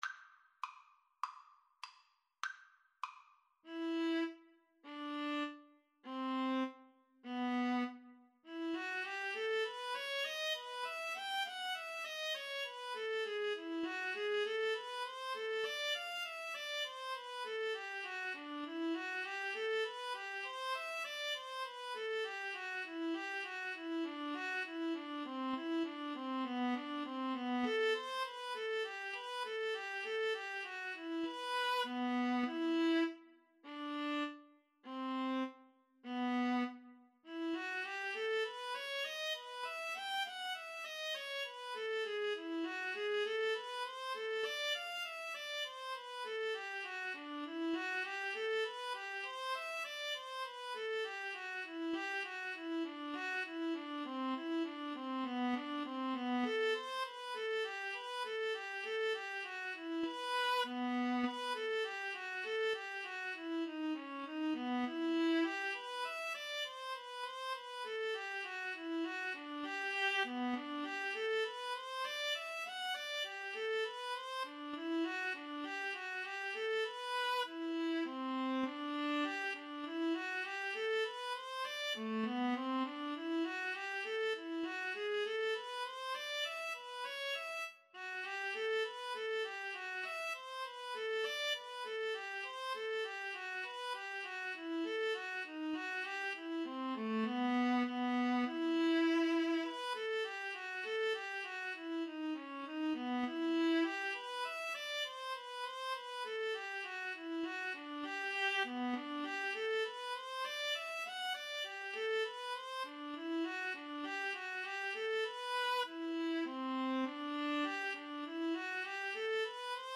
2/2 (View more 2/2 Music)
Classical (View more Classical Violin-Viola Duet Music)